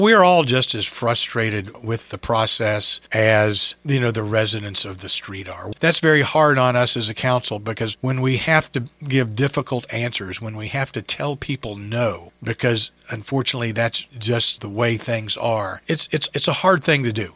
The future of the Washington Street Bridge is not very bright, though the City of Cumberland continues to negotiate with CSX to find a way to repair the bridge that trains damaged eight years ago, resulting in its closing.  Mayor Ray Morriss told WCBC that the residents of the area have reason to be upset, as does the city council since they live in the city as well…